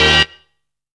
HALT STAB.wav